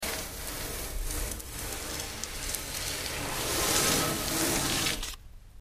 Scrape, Sand Bag
Sand Bag; Dragged, Scrape On Metal Deck.